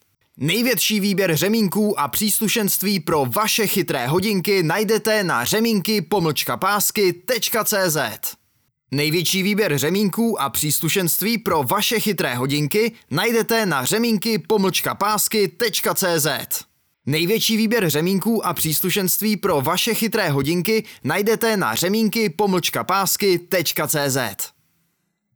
K dispozici mám profesionální mikrofon a zvuk jsem schopen sám upravit a poté odeslat v nejvyšší možné kvalitě.
Hlas pro Vaše video! (voiceover)